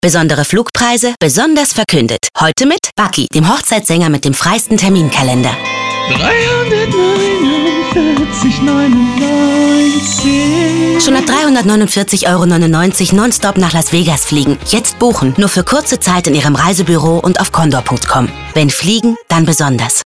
Radio spots: